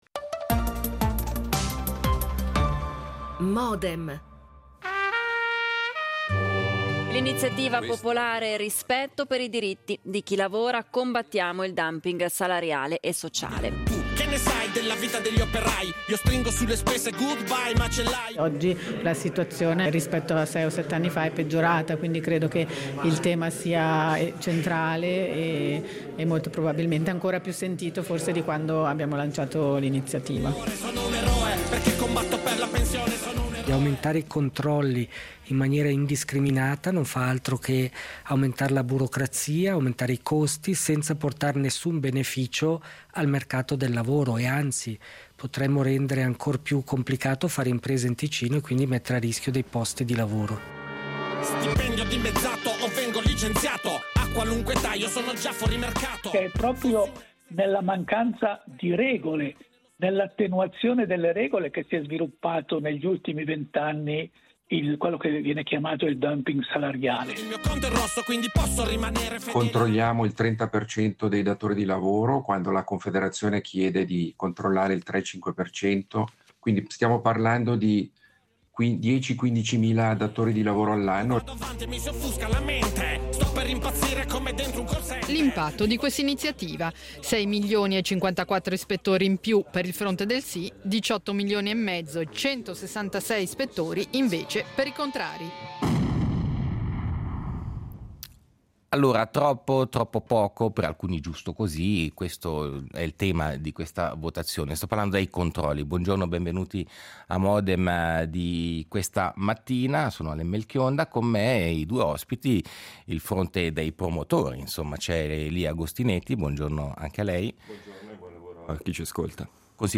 Dibattito in vista della votazione cantonale ticinese dell’8 marzo